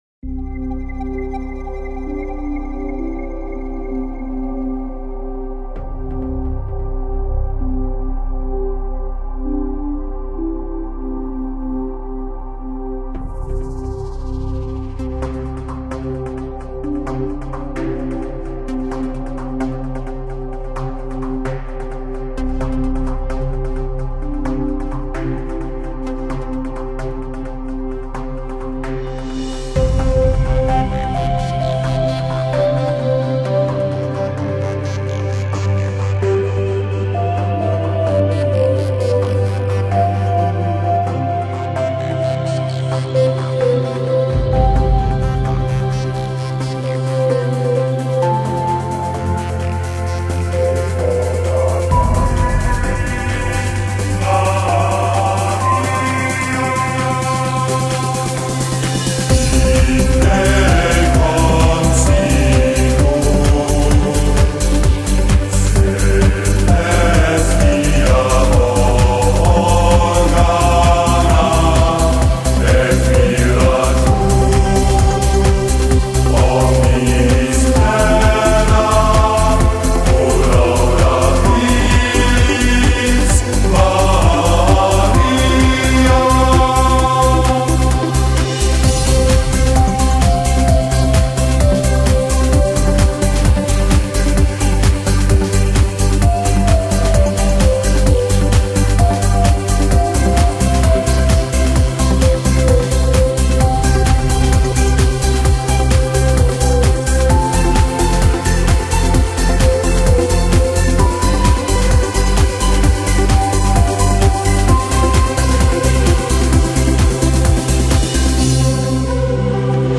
融入神秘，體驗神聖……